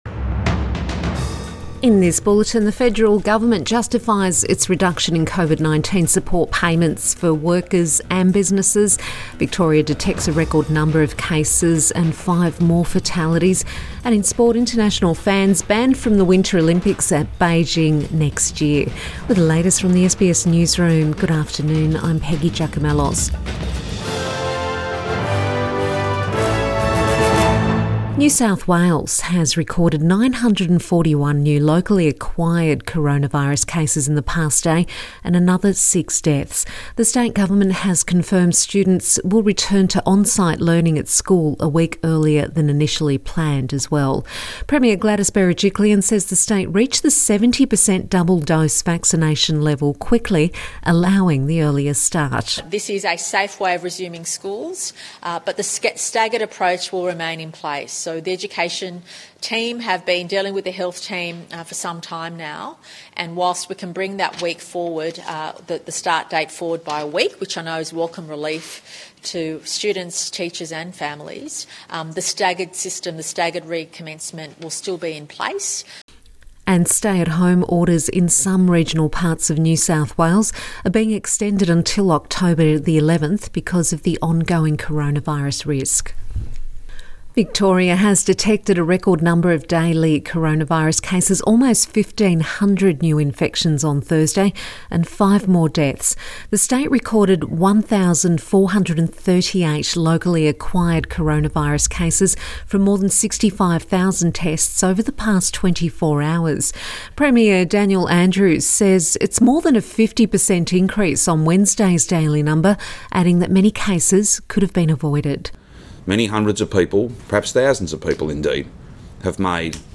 Midday bulletin 30 September 2021